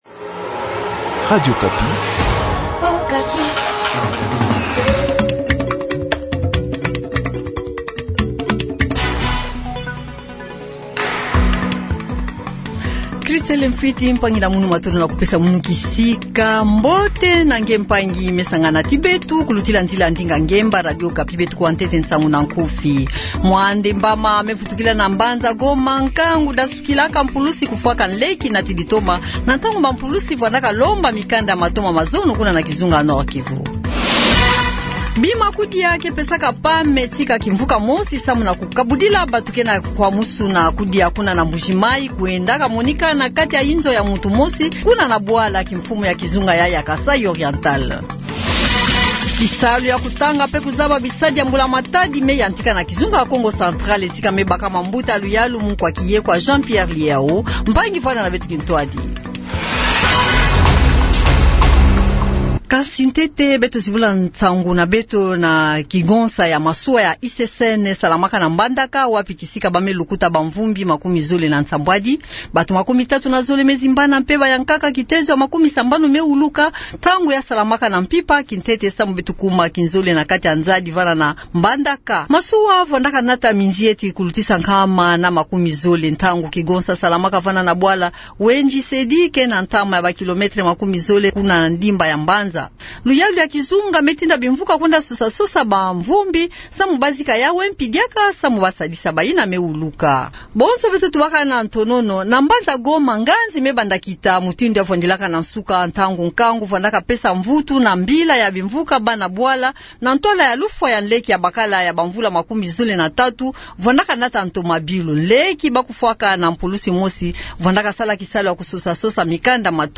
Journal du soir